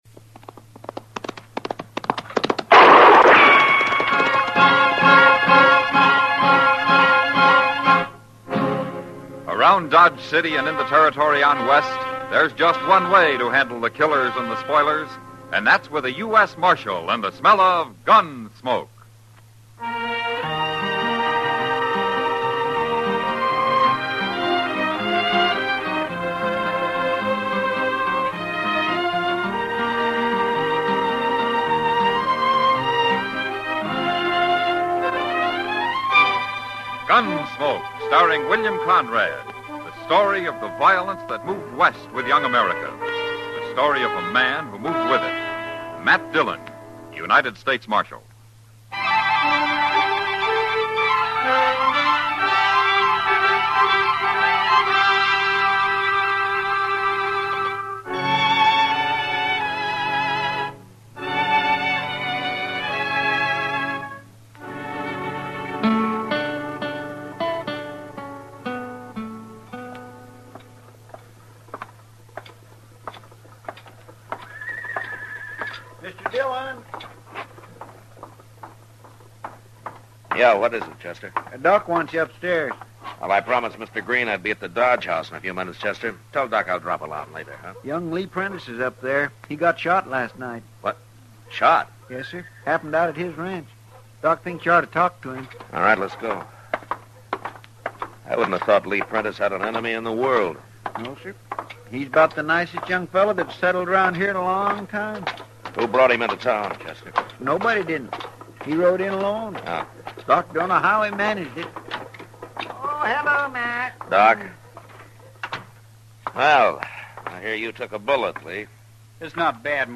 Gunsmoke is an American radio and television Western drama series created by director Norman Macdonnell and writer John Meston. The stories take place in and around Dodge City, Kansas, during the settlement of the American West. The central character is lawman Marshal Matt Dillon, played by William Conrad on radio and James Arness on television.